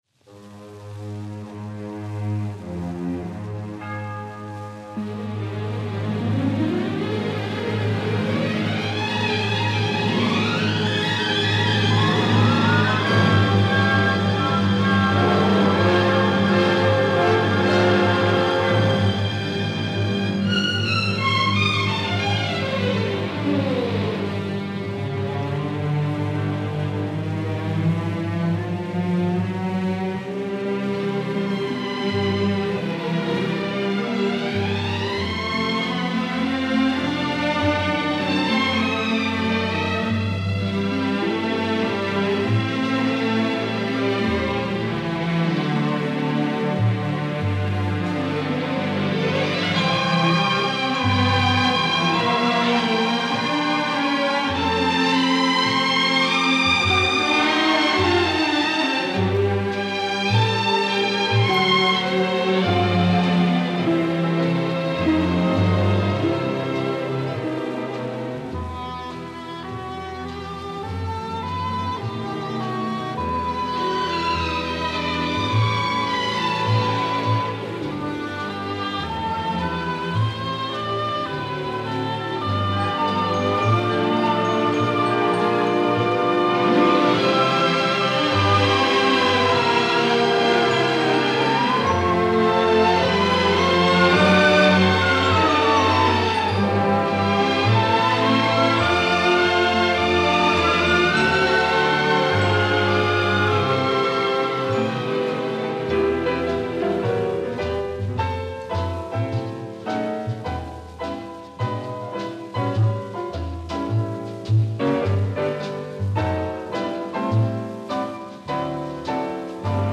Genre:Easy  Listeing